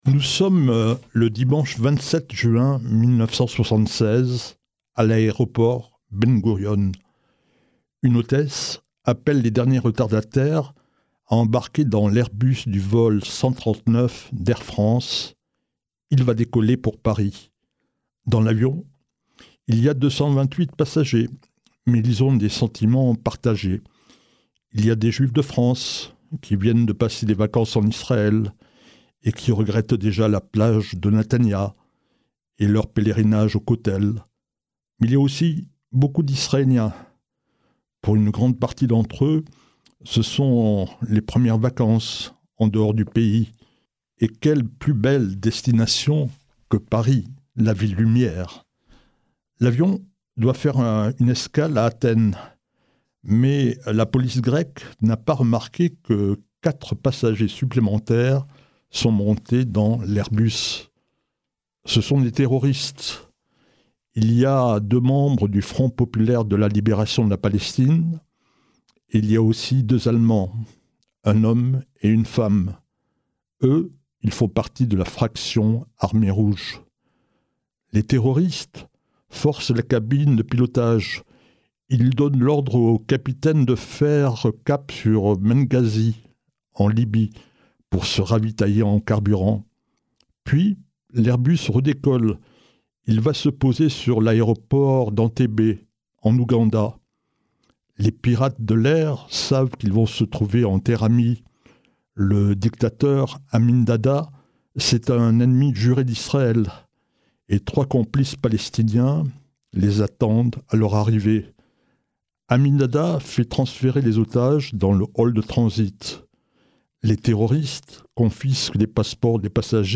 Dimanche 3 juillet dans le journal de 18h de Radio Shalom